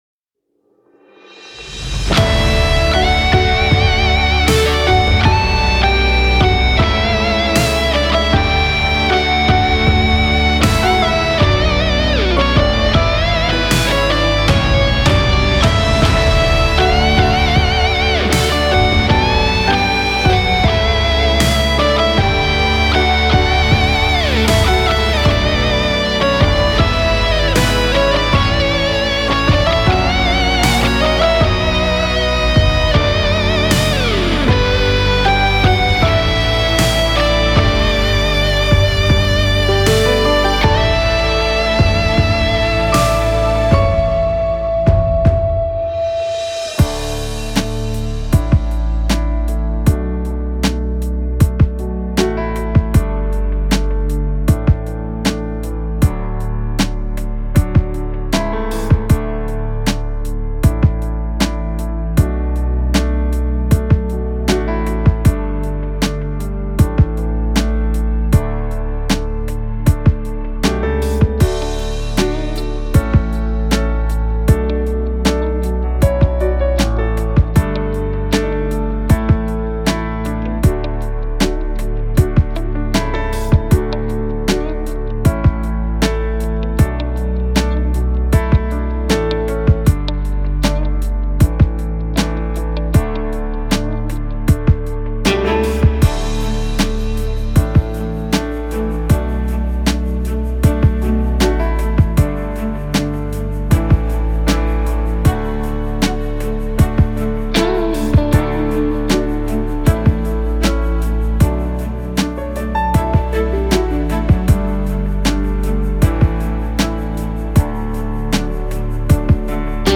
„Lietuva“ fonograma